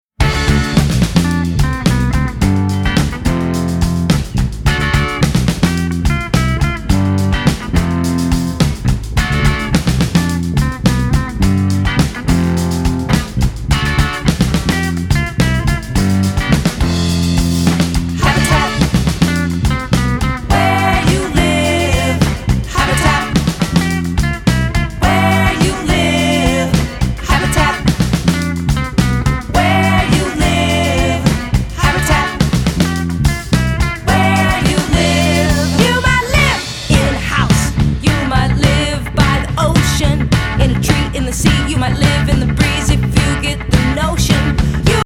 singable tunes and danceable rhythms